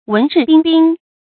注音：ㄨㄣˊ ㄓㄧˋ ㄅㄧㄣ ㄅㄧㄣ
文質彬彬的讀法